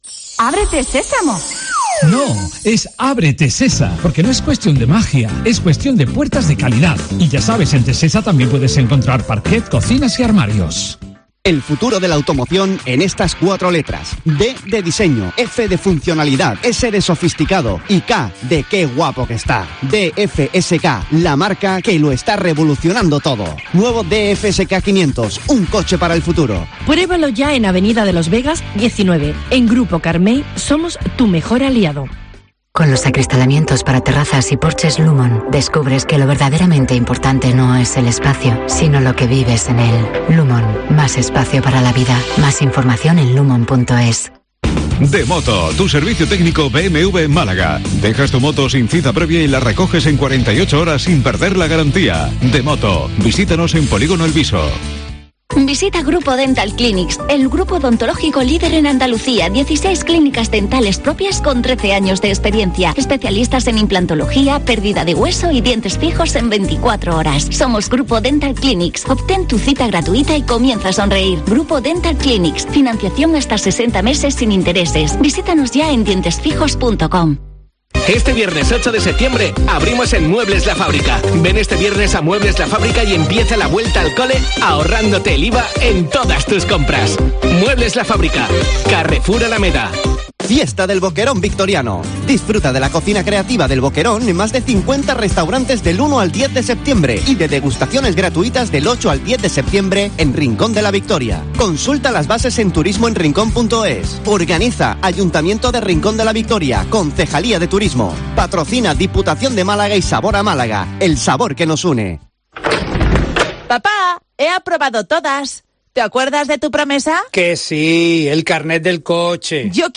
Informativo 12:50 Málaga 060923